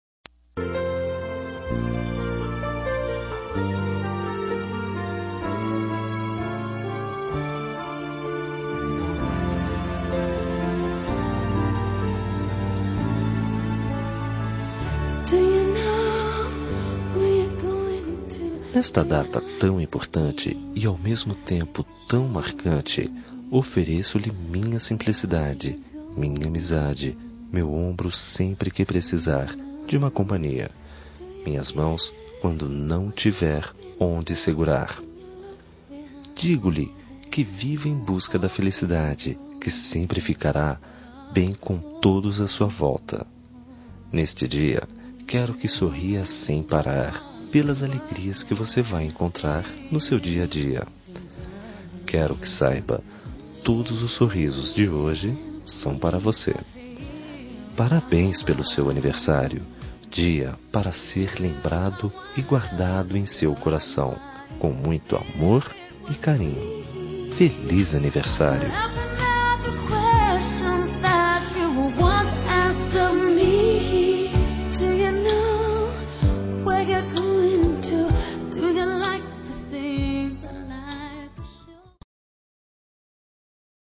Telemensagem de Aniversário de Amigo – Voz Masculina – Cód: 1616